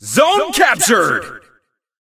announcer_vo_zonecaptured_01.ogg